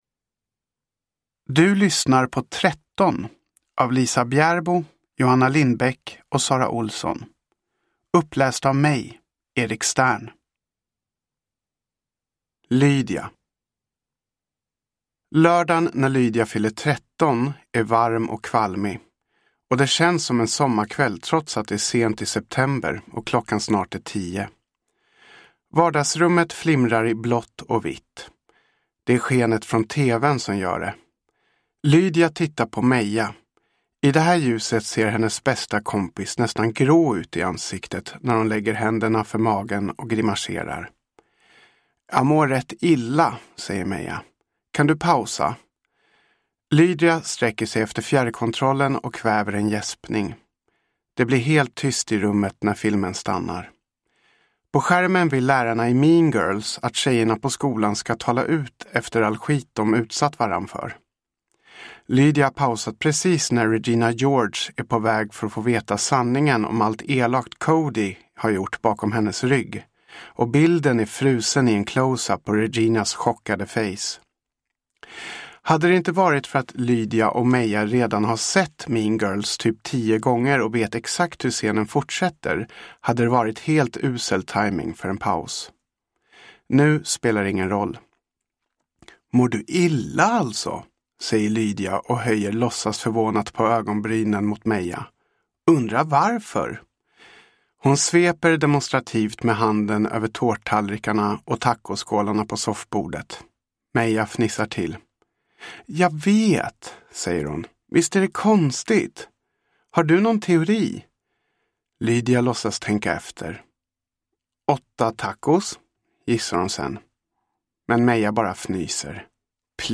Tretton – Ljudbok